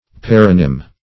Paronym \Par"o*nym\, n.